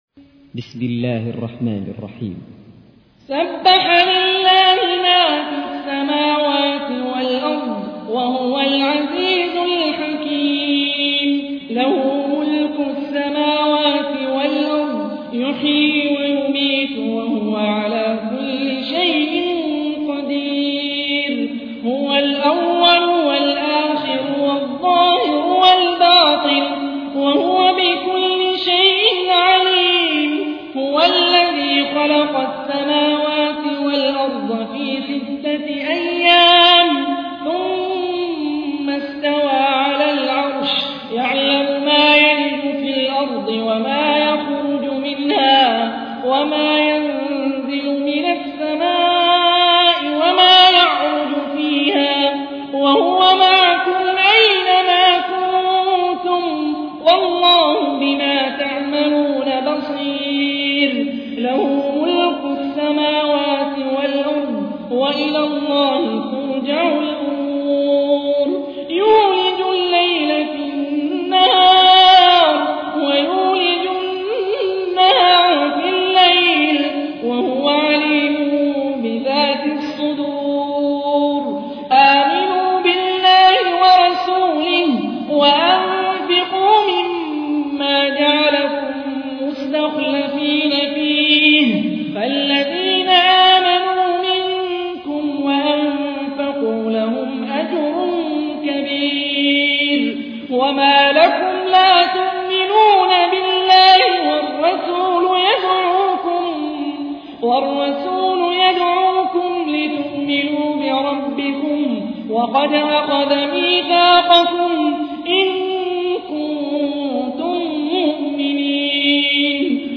تحميل : 57. سورة الحديد / القارئ هاني الرفاعي / القرآن الكريم / موقع يا حسين